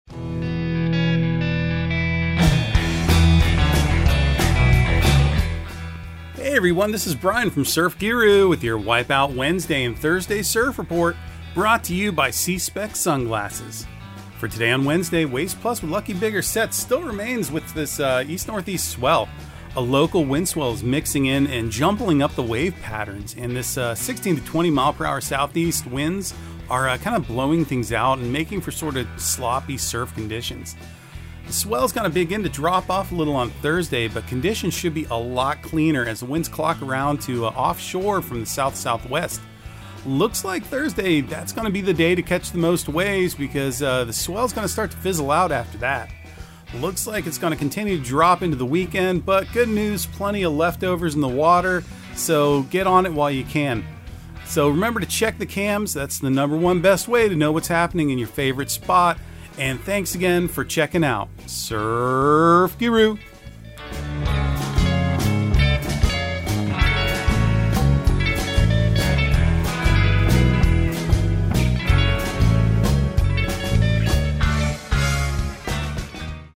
Surf Guru Surf Report and Forecast 12/14/2022 Audio surf report and surf forecast on December 14 for Central Florida and the Southeast.